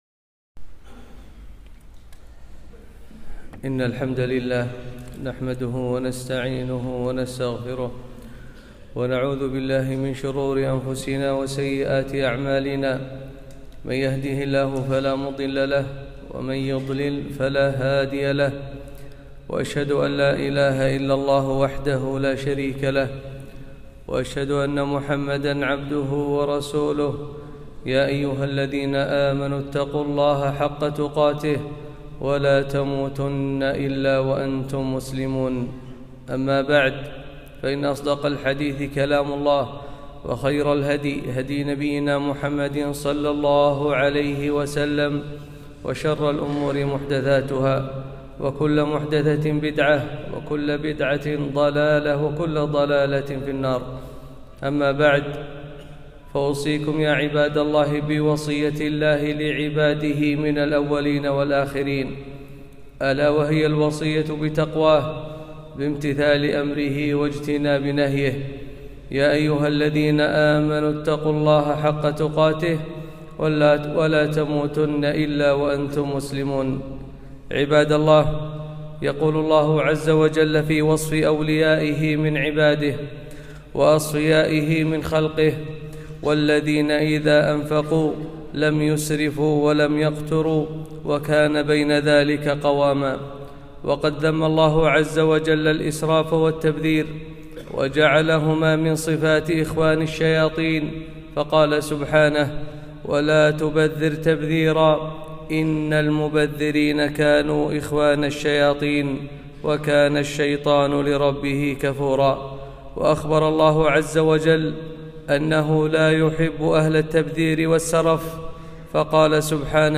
خطبة - ولا تسرفوا